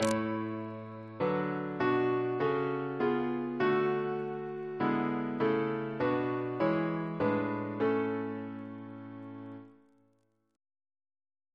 Single chant in A Composer: Charles Harford Lloyd (1849-1919), Organist of Gloucestor Cathedral amd Christ Church, Oxford, Precentor of Eton, Organist and composer to the Chapel Royal Reference psalters: ACB: 69